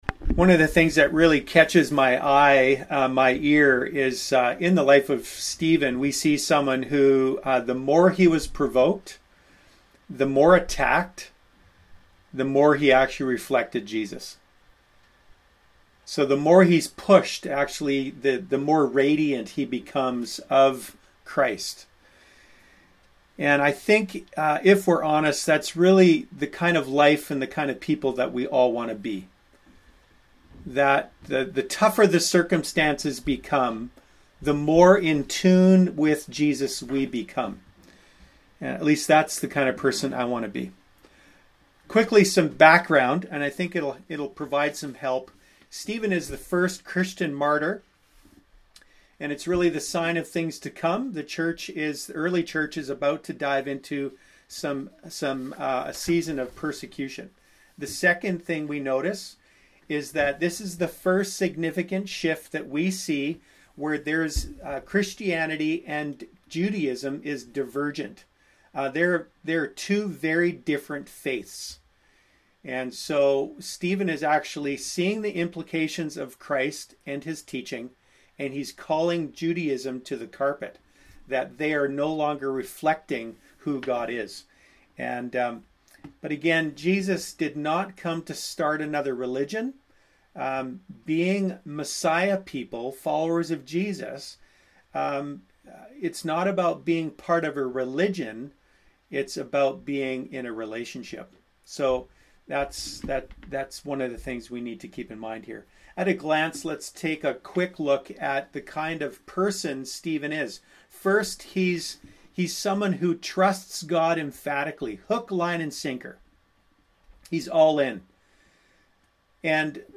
Sermons | The River Church